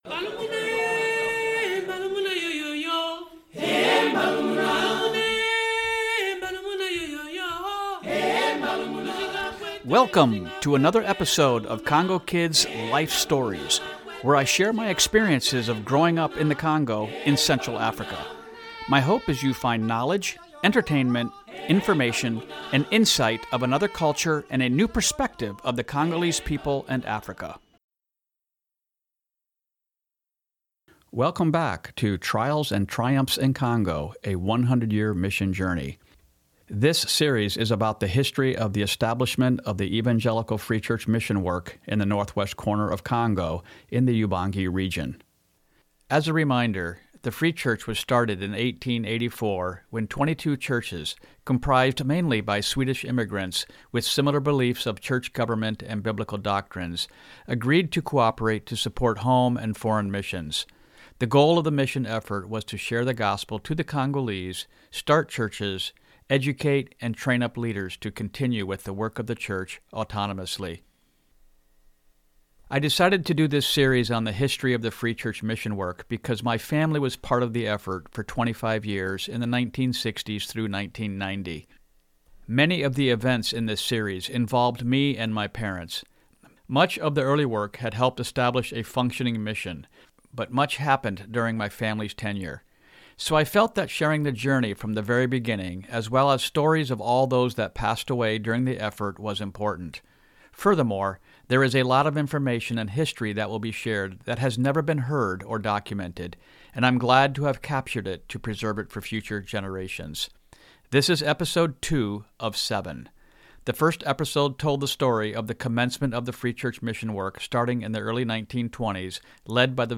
How these stations were purchased, constructed and their ministries will be explained by people that lived there. Short wave radios became a key part of inter-station communications during this time as well.